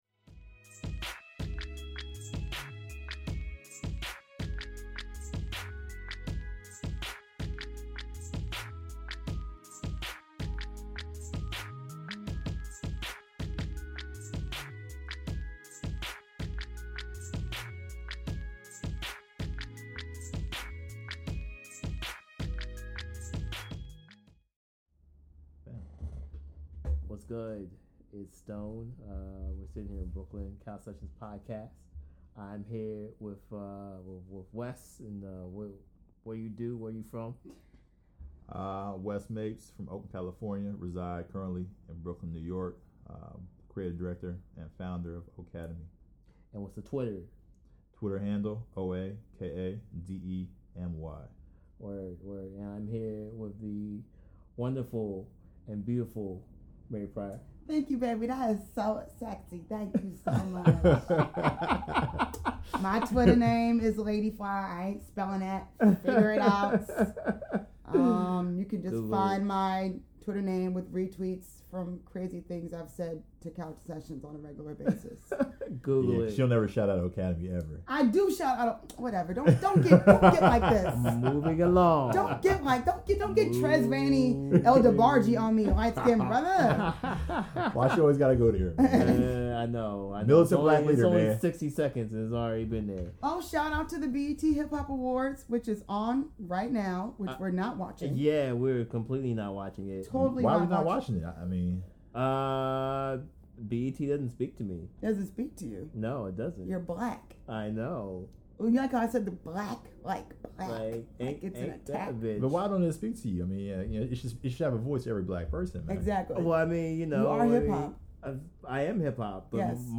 We are three unabashed music loves who can share a passion for both Mad Men and Rick Ross, for DMX and Little Dragon. We are highly opinionated and we won’t hold anything back.